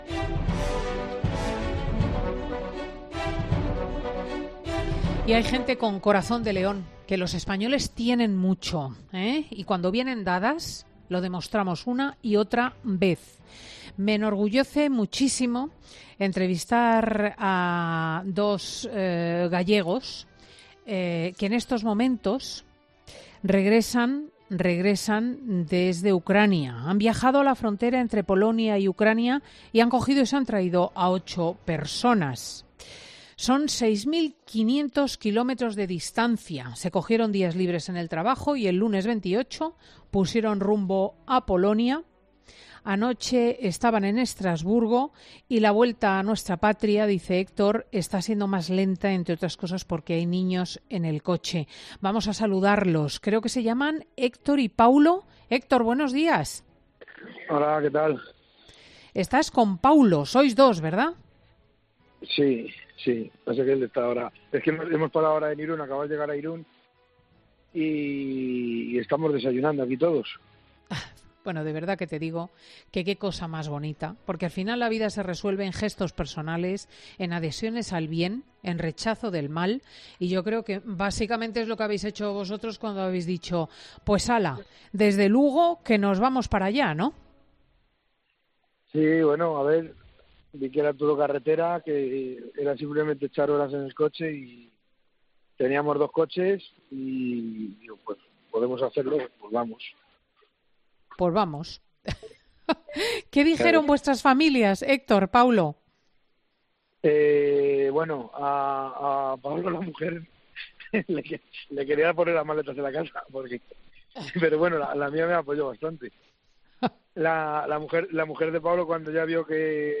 Ambos han hecho un alto en el camino este sábado para desayunar y para contar su experiencia en Fin de Semana: